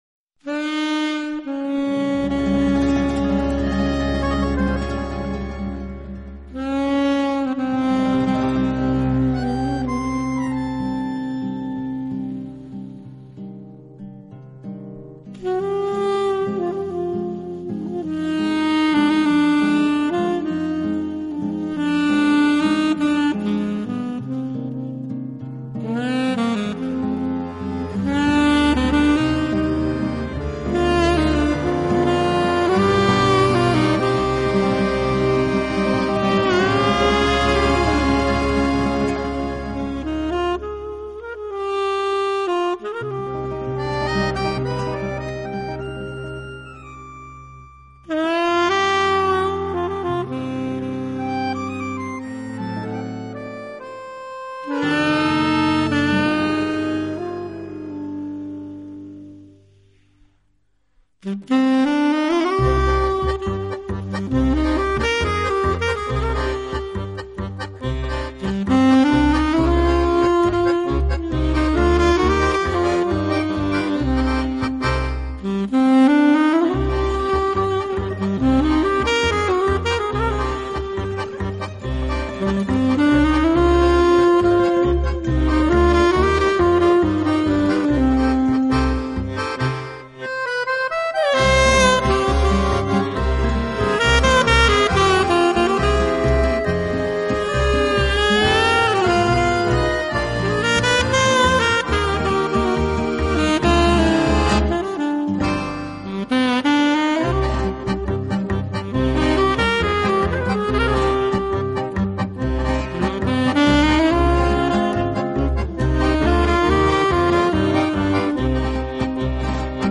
现代沙龙音乐：巴萨诺瓦，探戈，法式华尔兹融合意大利和西班牙的印象。
Saxophon
Gitarre)在Paco de Lucia
(Kontrabass)节奏感极佳，他的贝司时常被用作打击乐器。
(键盘, 主唱)忧郁的嗓音充满魔力，经常让他的乐器只发出呼吸般的声音。
beguine napolitano